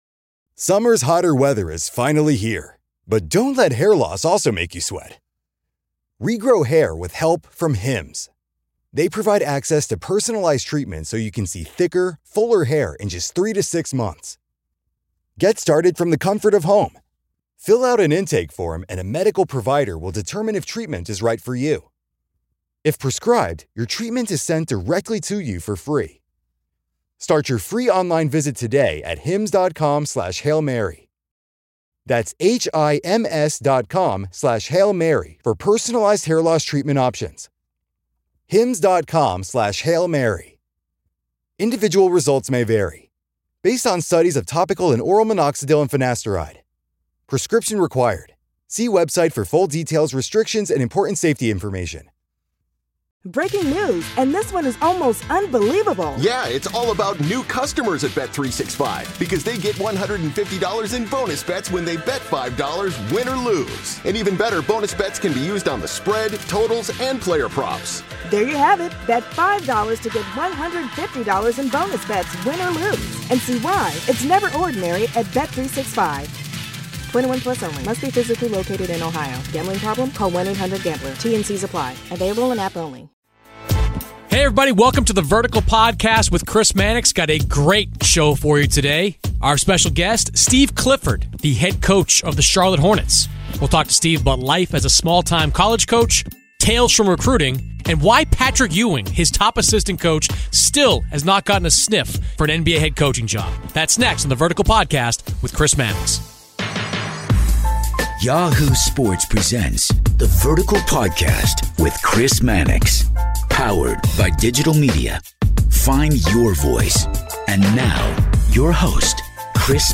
Charlotte Hornets Head Coach Steve Clifford joins the Vertical Podcast
On this week's episode of The Vertical Podcast with Chris Mannix, Charlotte Hornets head coach Steve Clifford joins the program.